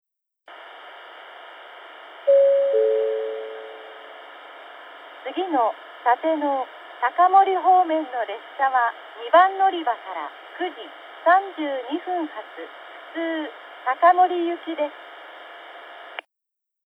遠隔型放送C（高森行き）
放送は1回のみ流れます。
スピーカーは1番のりばがTOAの灰色のラッパ型、2番のりばがカンノボックス型でしたが、現在は1番のりばはUNI-PEXクリアホーン、2番のりばはソノコラム（ミニ）に取り換えられています。